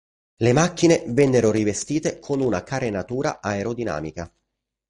a‧e‧ro‧di‧nà‧mi‧ca
/ˌa.e.ro.diˈna.mi.ka/